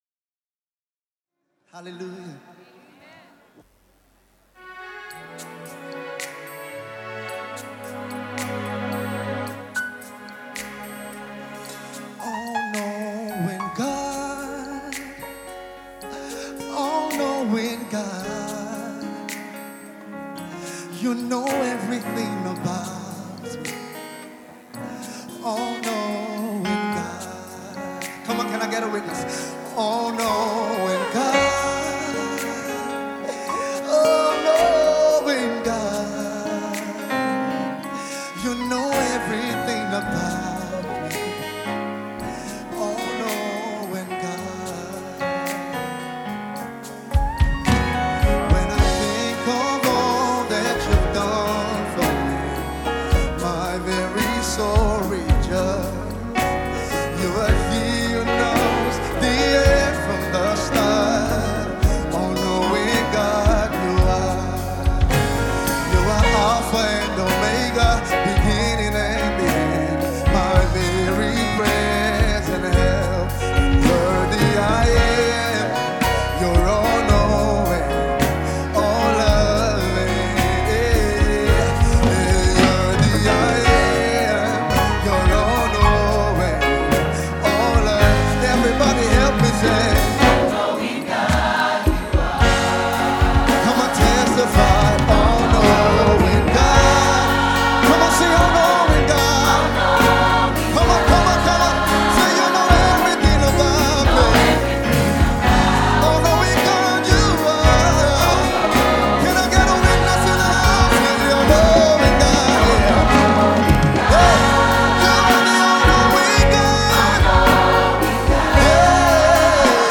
Nigerian Gospel